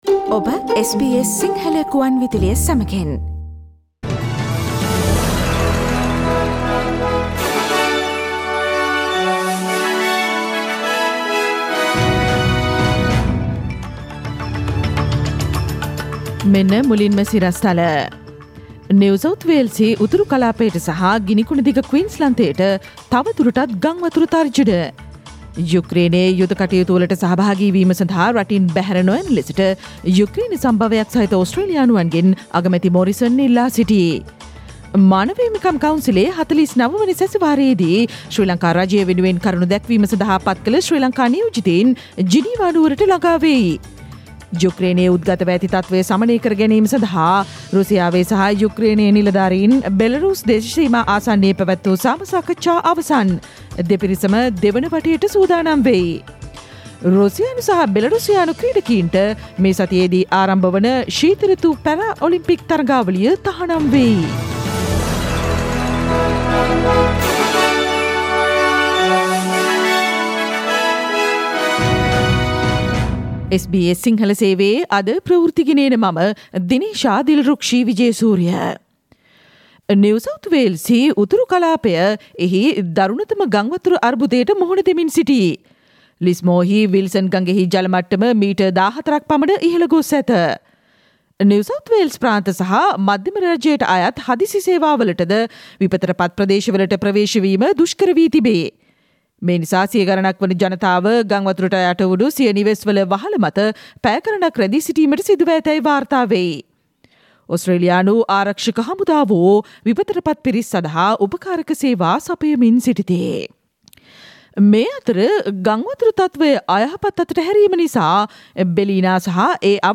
ඉහත චායාරූපය මත ඇති speaker සලකුණ මත click කොට මාර්තු 1 වන අඟහරුවාදා SBS සිංහල ගුවන්විදුලි වැඩසටහනේ ප්‍රවෘත්ති ප්‍රකාශයට ඔබට සවන්දිය හැකියි.